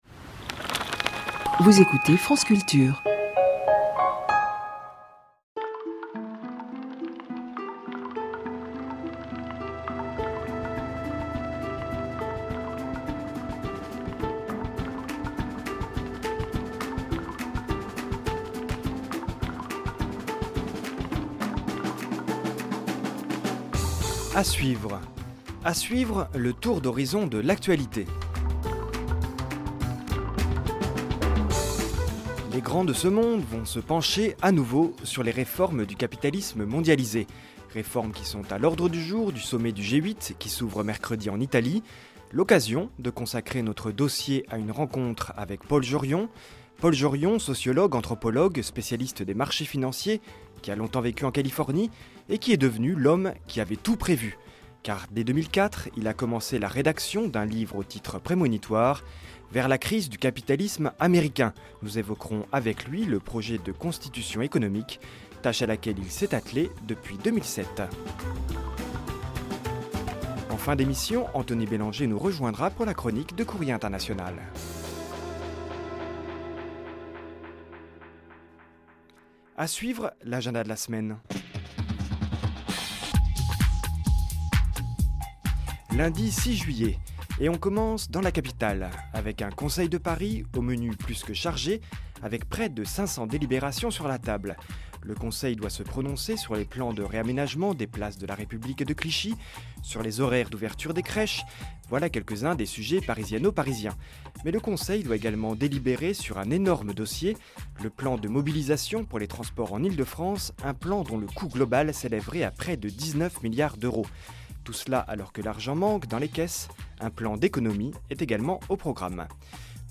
J’étais ce matin l’invité de Marc Voinchet aux Matins de France Culture.